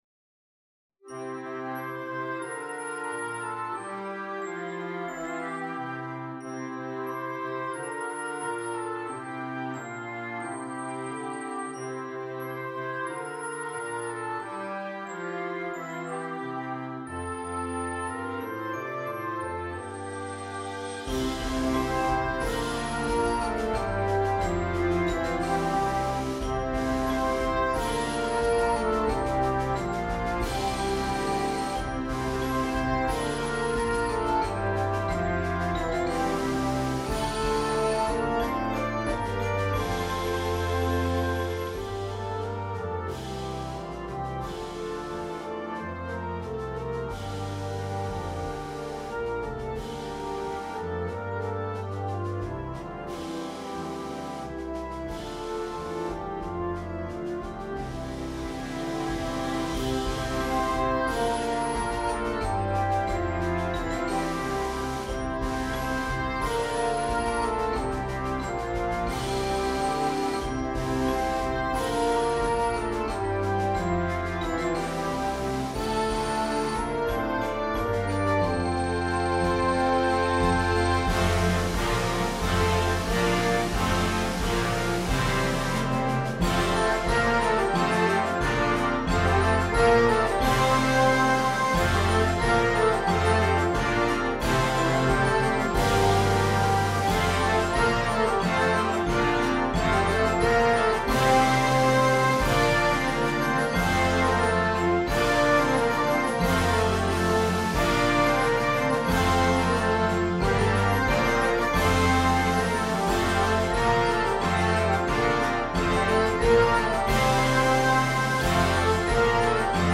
This original holiday concert band selection is grade 2.